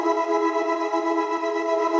SaS_MovingPad05_120-E.wav